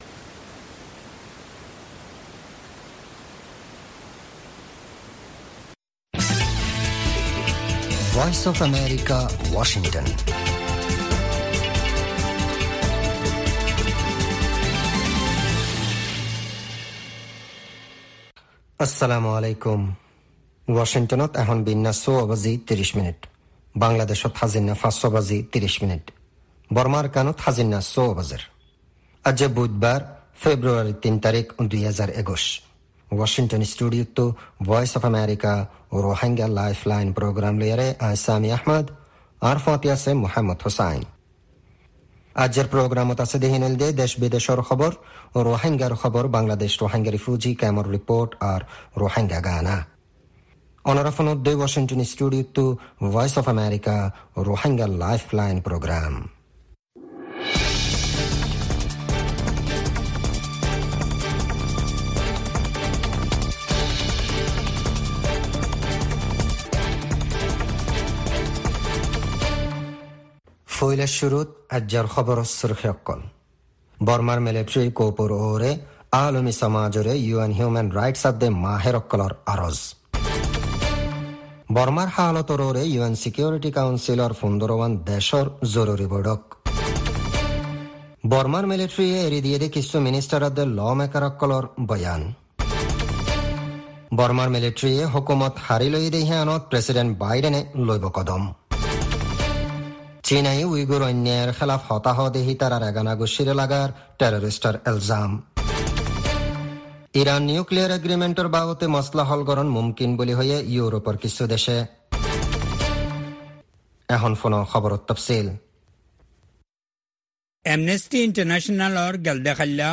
Rohingya Broadcast
News Headlines